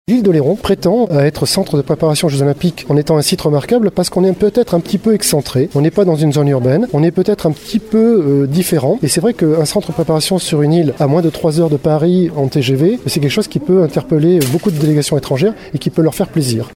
Lors du lancement du projet mardi dernier.
L’île d’Oléron dispose de nombreux atouts pour accueillir les délégations sportives durant leur préparation aux JO, comme le souligne le maire de Saint-Pierre et vice-président de la CdC Christophe Sueur :